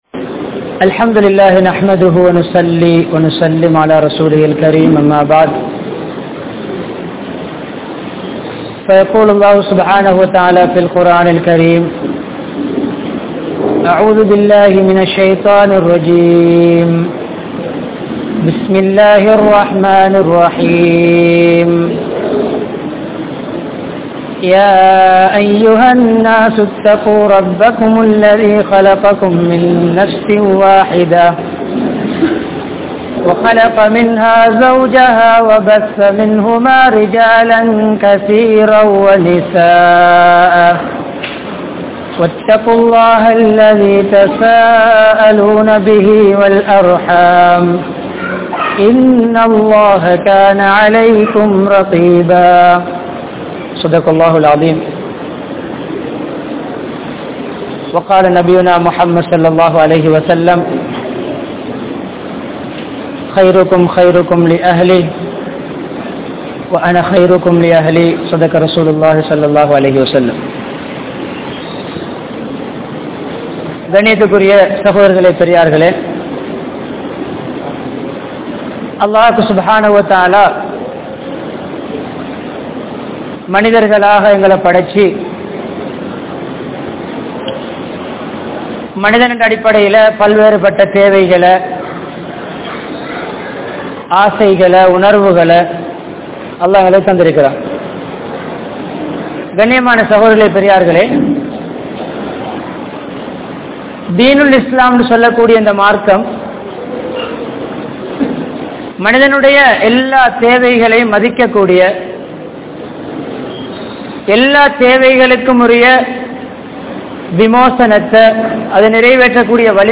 Mahilchiyaana Kudumba Vaalkai (மகிழ்ச்சியான குடும்ப வாழ்க்கை) | Audio Bayans | All Ceylon Muslim Youth Community | Addalaichenai
Colombo13, Kotahena, Shoe Road Jumua Masjidh